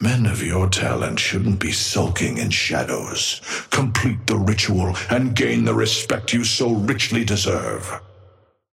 Amber Hand voice line - Men of your talents shouldn't be sulking in shadows.
Patron_male_ally_krill_start_02.mp3